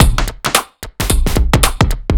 OTG_Kit 4_HeavySwing_110-A.wav